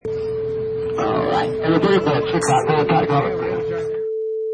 Shuttle ride announcement from driver
Product Info: 48k 24bit Stereo
Category: Human / Vocal Sounds - Loudspeakers
Try preview above (pink tone added for copyright).
Shuttle_Ride_Announcement_4.mp3